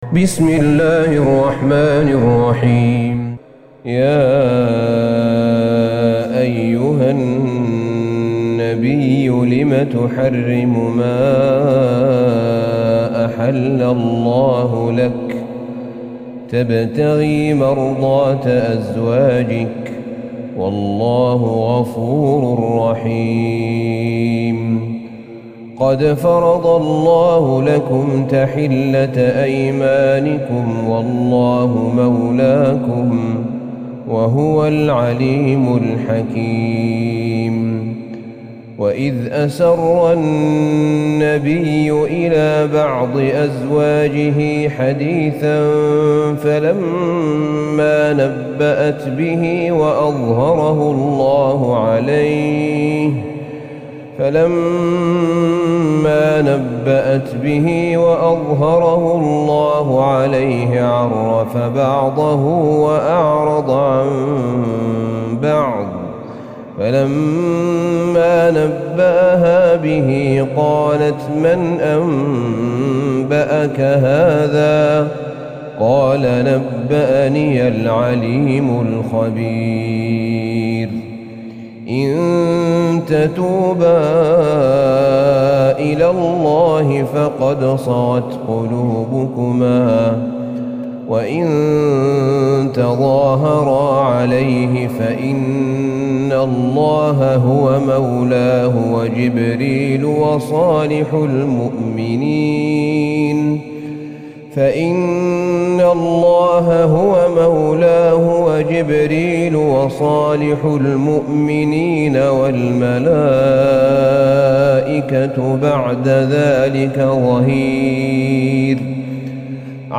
سورة التحريم Surat At-Tahrim > مصحف الشيخ أحمد بن طالب بن حميد من الحرم النبوي > المصحف - تلاوات الحرمين